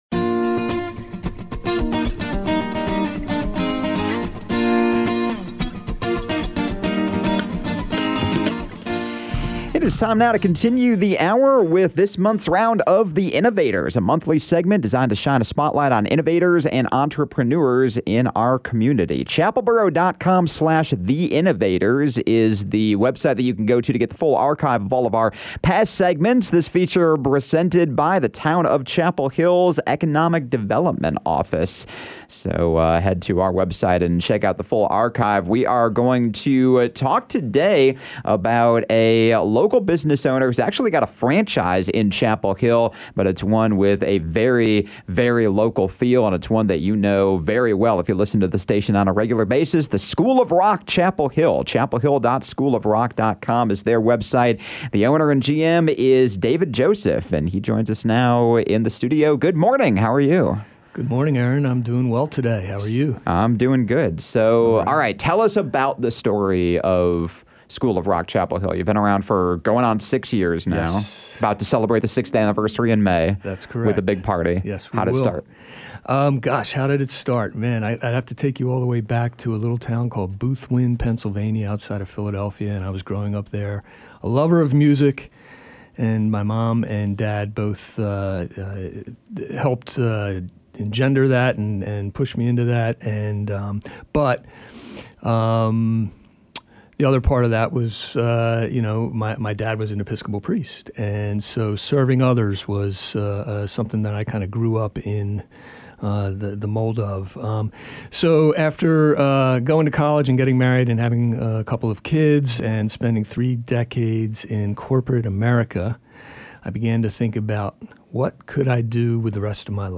Listen below for the full conversation, and to hear the details about the School of Rock Chapel Hill — along with thoughts on innovation, business growth and more! https